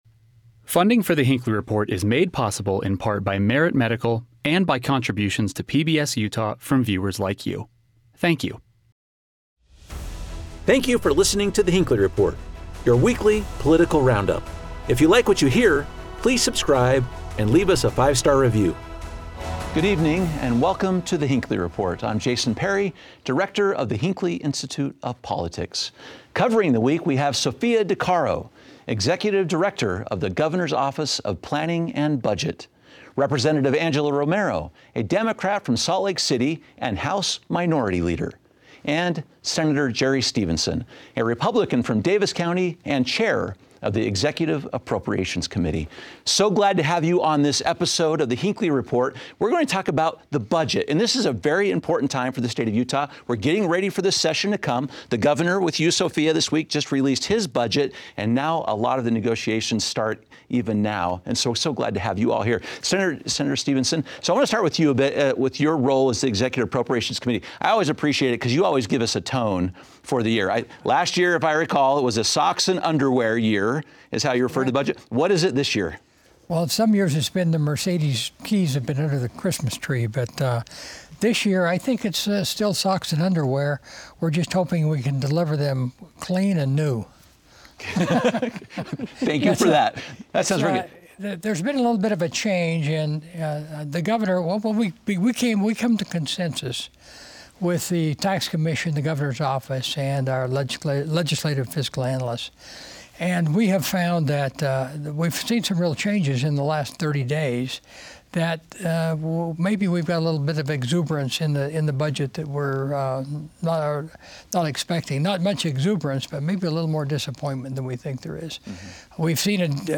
In this episode, our expert panel examines the Beehive State's budgeting process. Learn how legislative leaders negotiate with the governor's office and find out who gets the final say on how your tax dollars actually get spent.